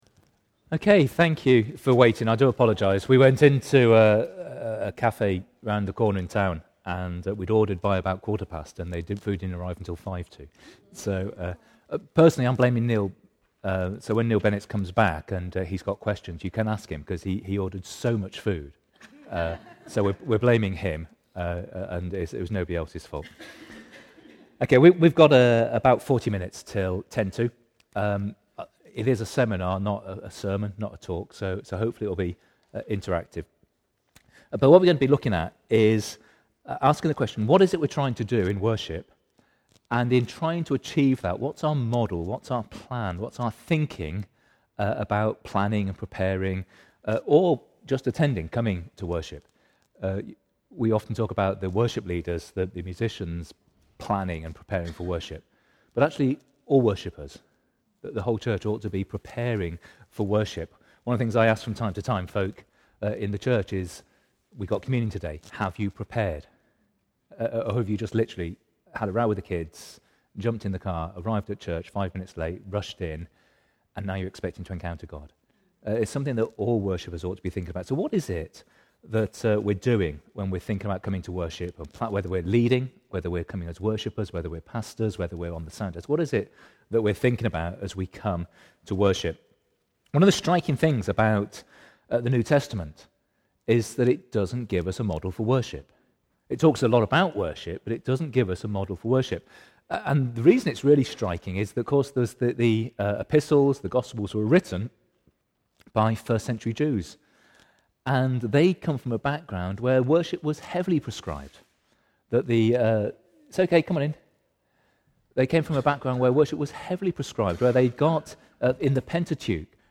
Seminar RG Main